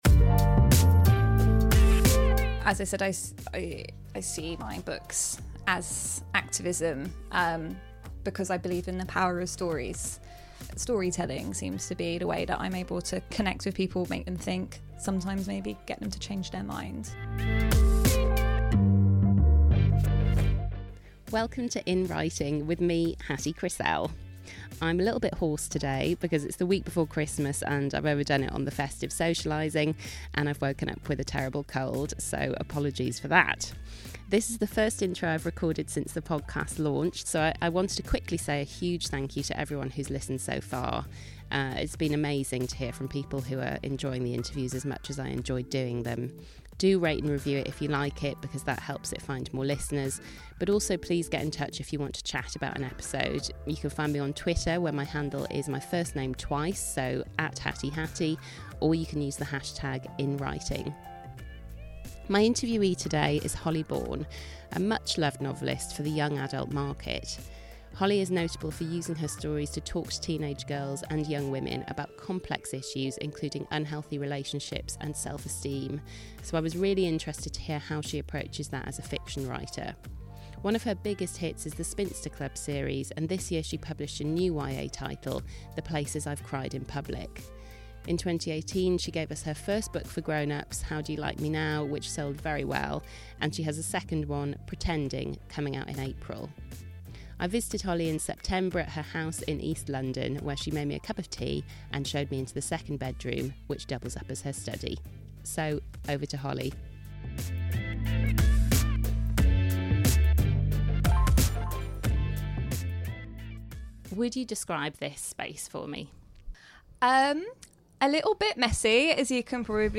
Holly Bourne invites me into her study/spare room to talk about her prolific career as a YA writer – and her recent novels for adults, including the bestselling How Do You Like Me Now?. She explains why her writing is a kind of activism, and why being honest about life – including all the sex and swearing – is the only way to get through to readers in the most vulnerable moments of their teenage years.